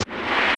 crawl_dirt.wav